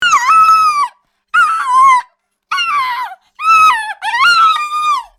Dog Screaming In Pain Sound Effect Download: Instant Soundboard Button
Dog Sounds1,571 views